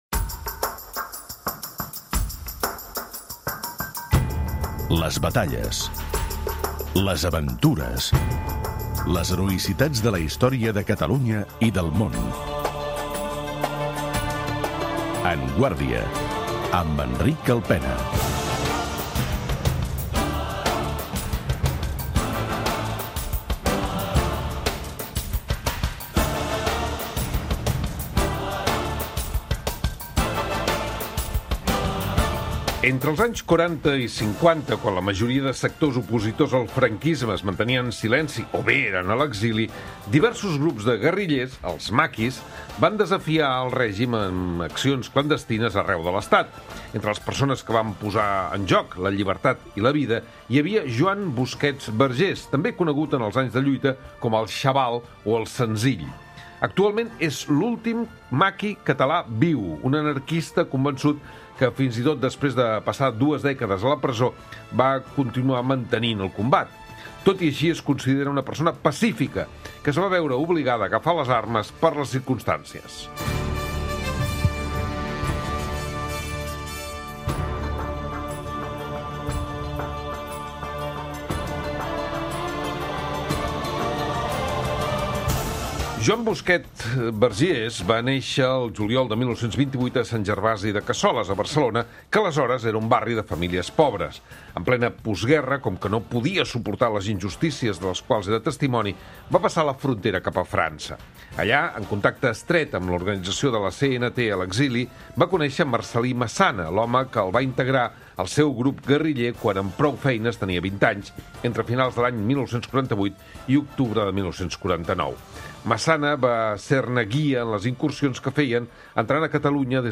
En parlem amb l'historiador Josep Maria Solé i Sabaté